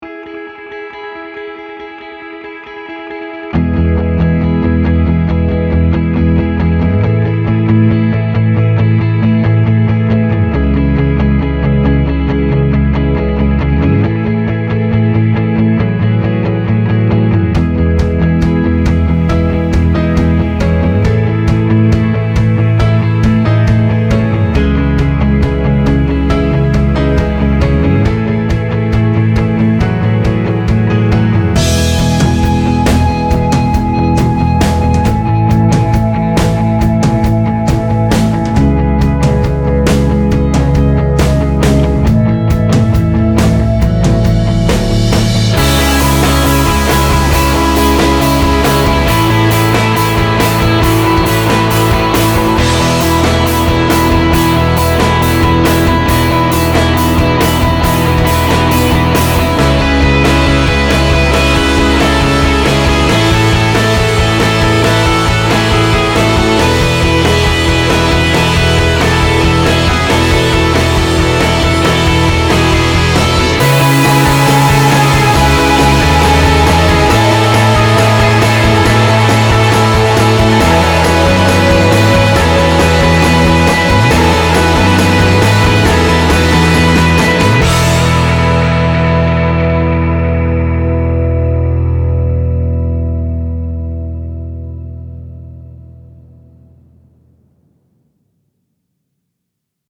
INDIE ROCK - INSTRUMENTALS